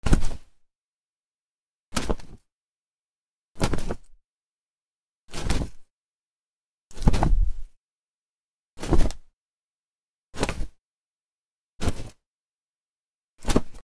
布を振る
/ J｜フォーリー(布ずれ・動作) / J-05 ｜布ずれ
『バサ』